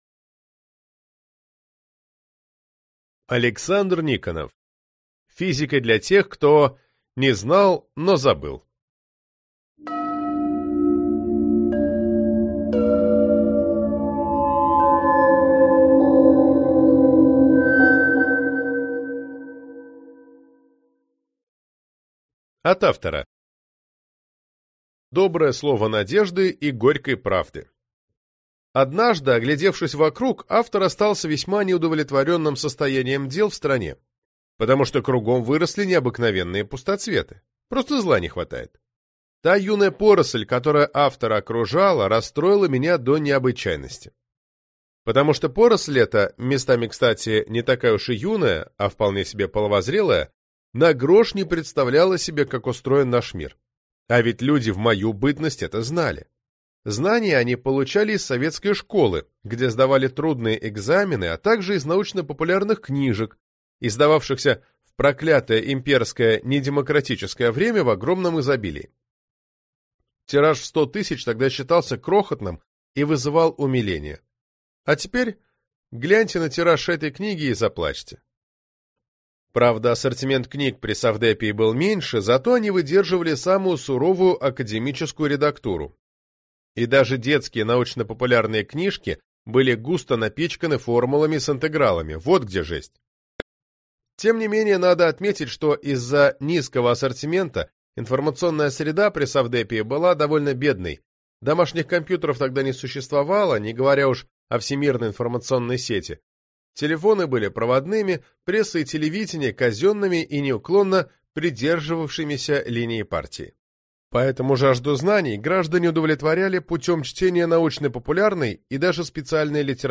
Аудиокнига Физика для тех, кто (не) знал, но забыл | Библиотека аудиокниг